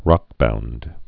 (rŏkbound)